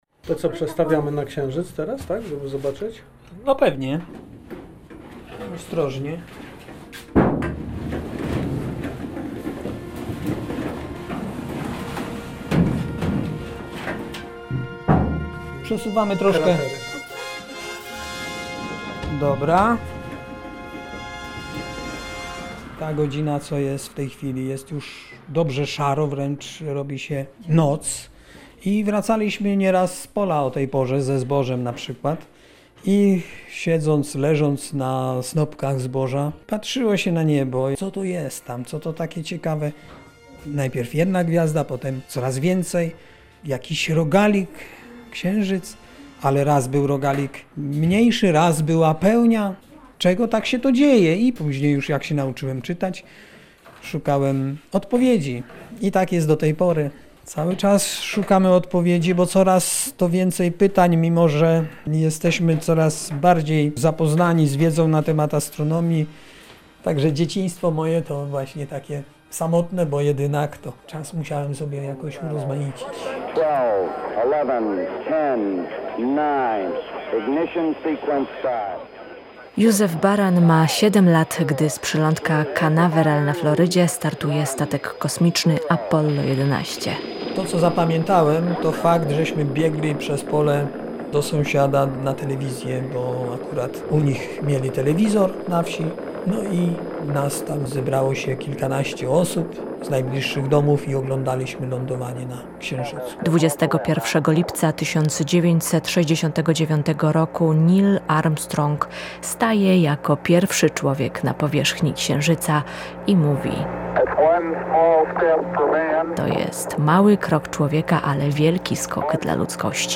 Fot. archiwum Łowcy gwiazd Tagi: reportaż